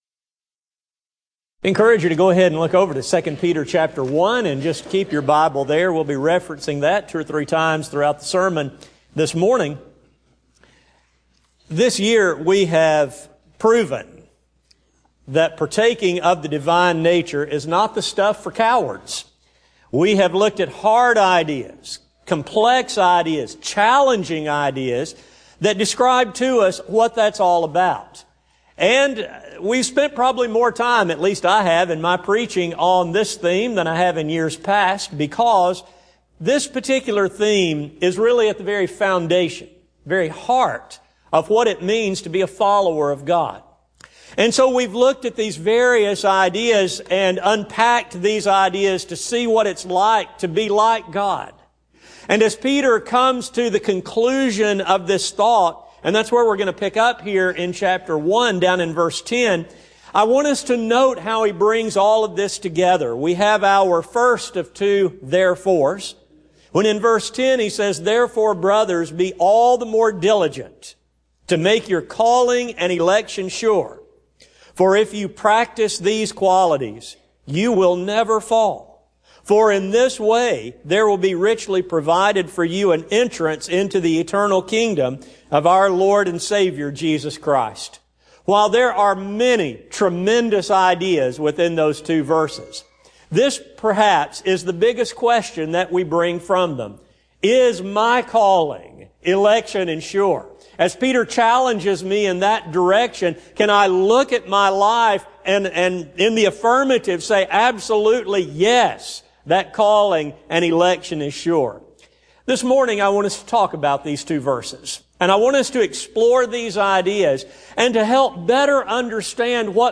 Partakers of the Divine Nature Service: Sun AM Type: Sermon Speaker